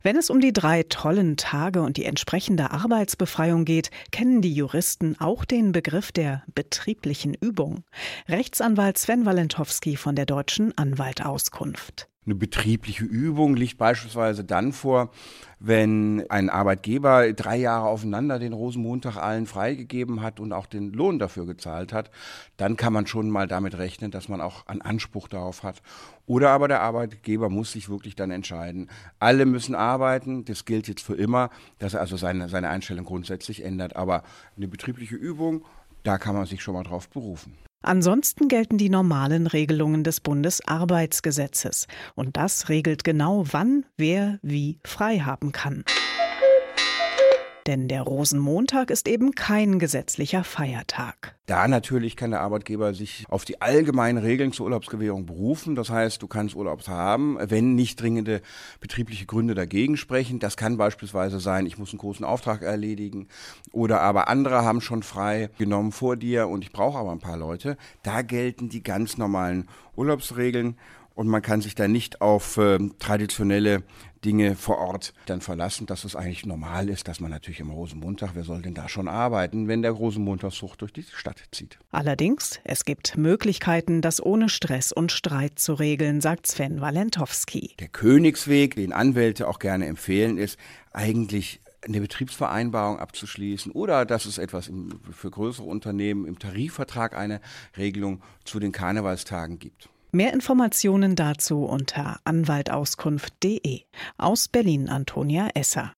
O-Töne / Radiobeiträge, , , , , ,
Magazin: Rosenmontag ist kein Feiertag